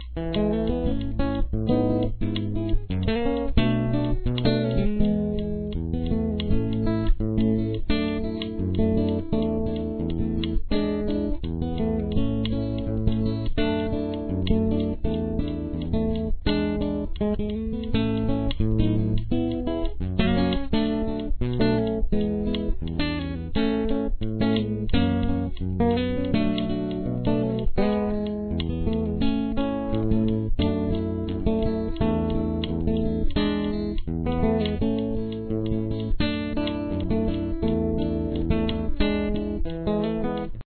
NO PICK on this one guys and gals!
The acoustic is in standard tuning.
Verse Theme